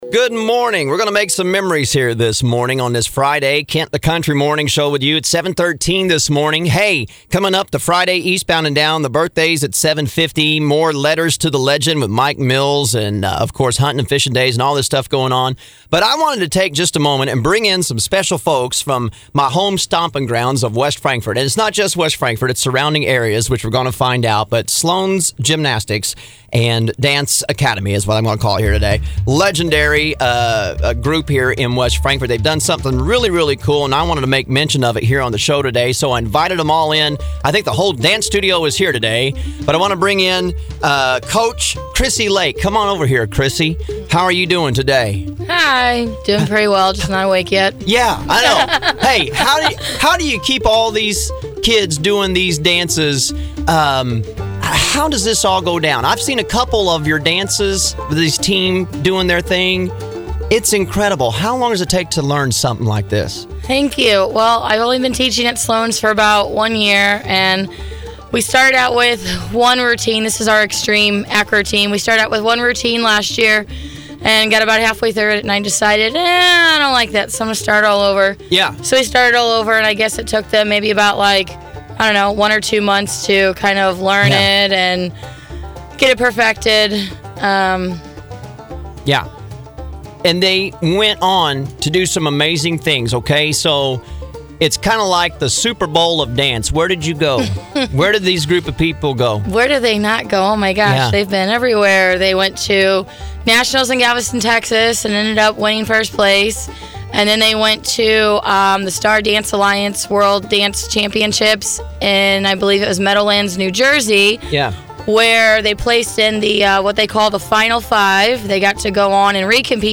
The X-TREME Dance Team from Sloan’s Gymnastics in West Frankfort joined me in the studio this morning! This group of dancers made it all the way to the World Dance Championships in Meadowlands, NJ this year!